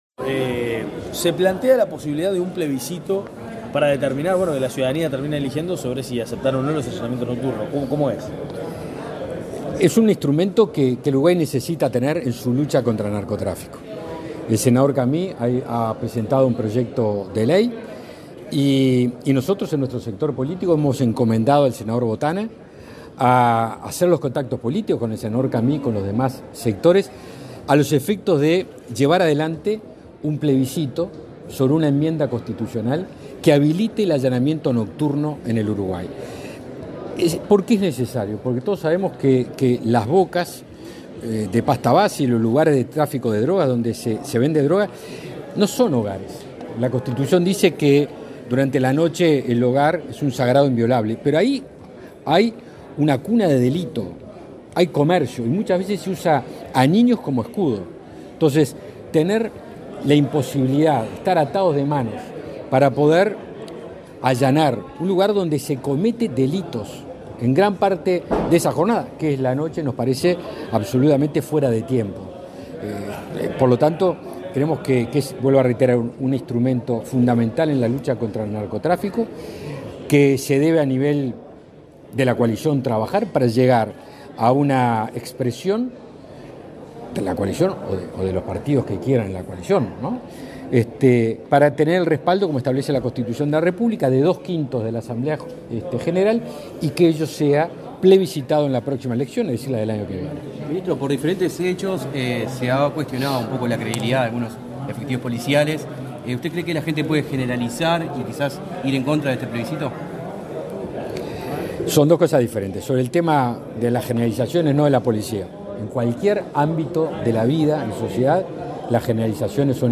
Declaraciones del ministro de Defensa Nacional, Javier García
Luego dialogó con la prensa.